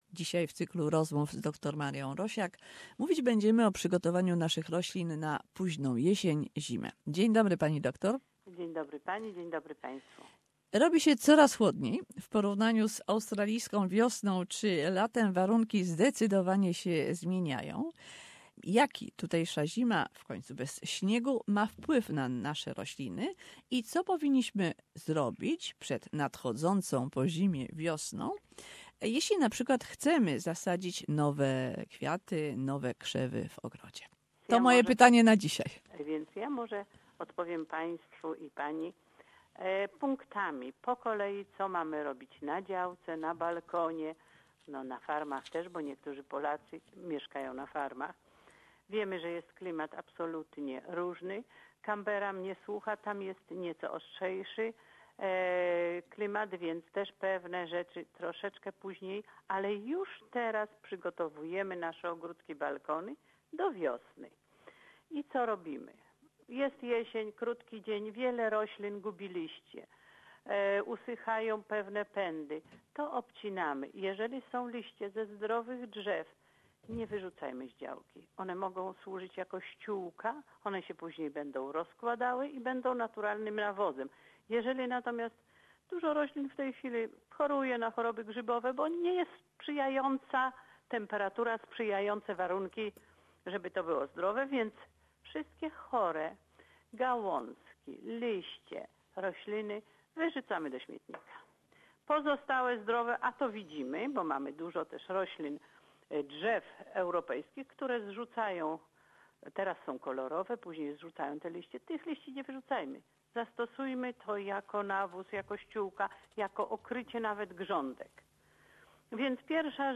Jak przygotować rośliny w ogrodzie czy na balkonie do zimy. Rozmowa